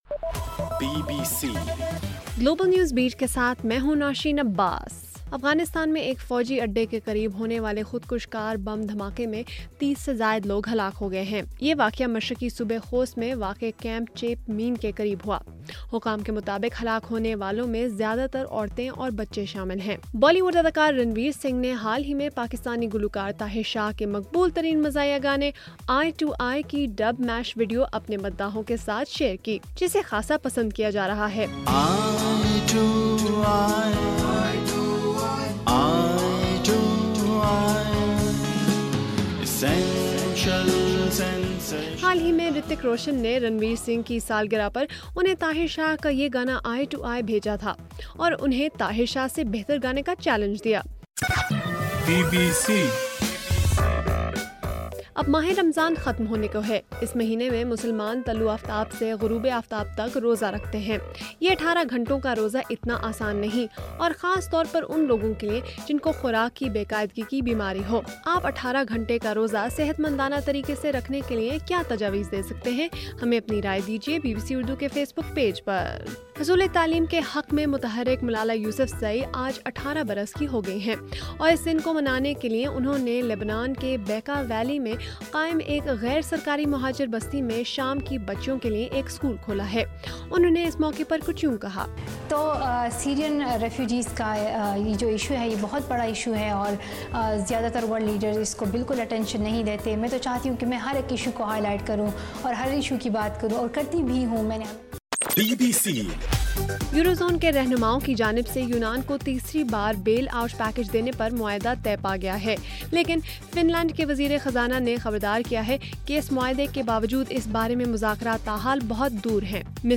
جولائی 13: رات 12 بجے کا گلوبل نیوز بیٹ بُلیٹن